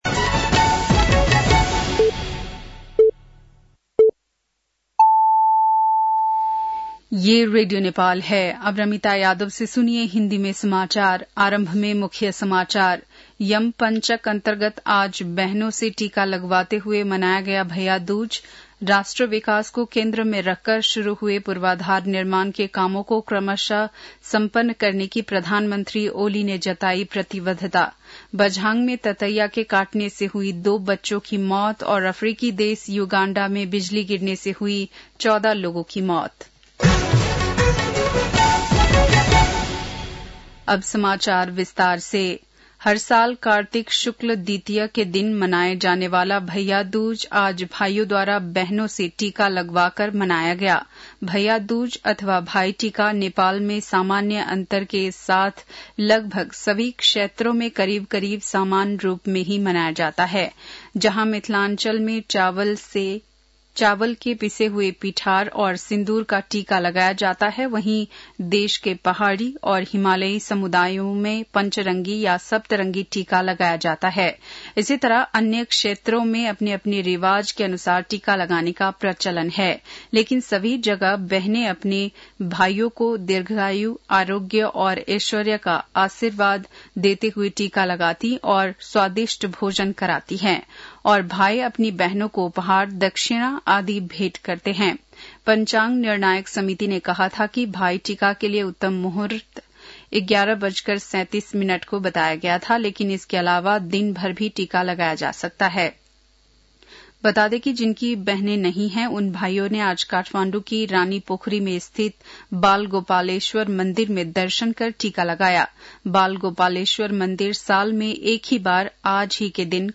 बेलुकी १० बजेको हिन्दी समाचार : १९ कार्तिक , २०८१
10-pm-hindi-news-7-18.mp3